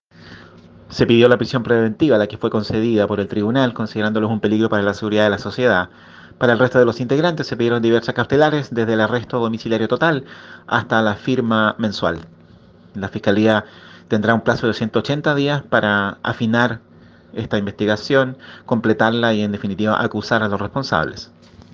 Voz Fiscal Pérez.